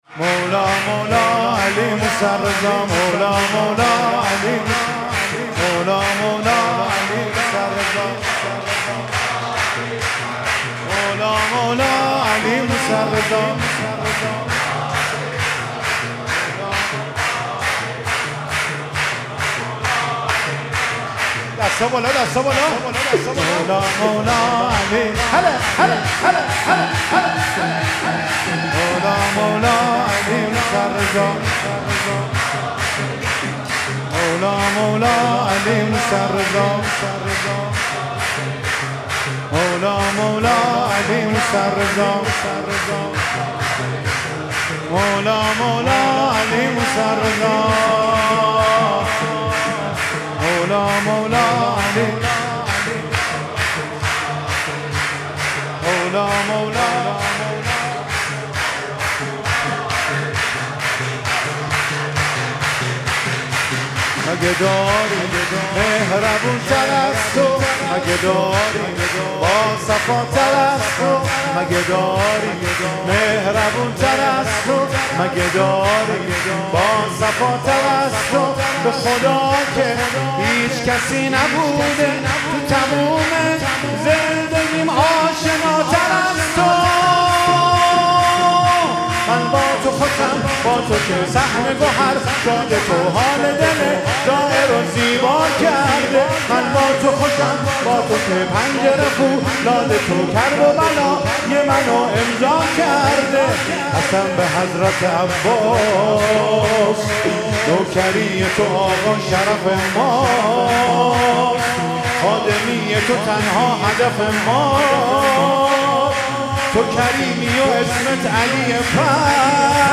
سرود مولودی ولادت امام رضا (ع) 1404